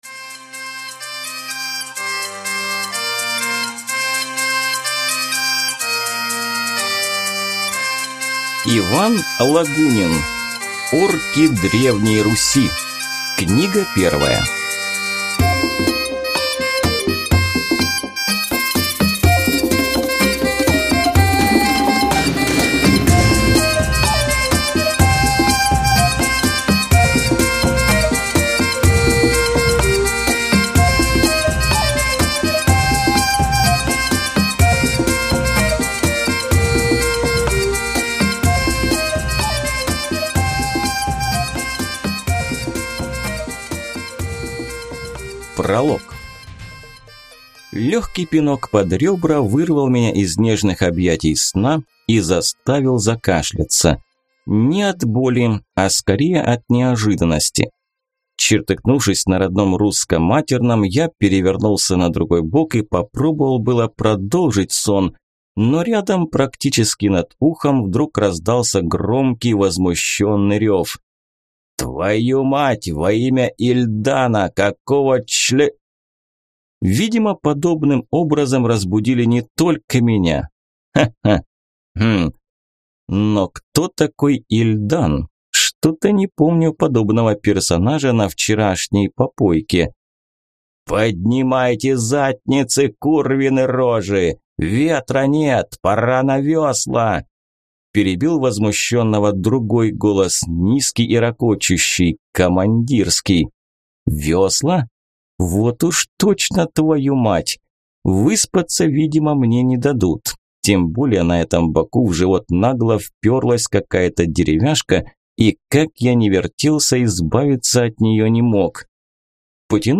Аудиокнига Орки Древней Руси | Библиотека аудиокниг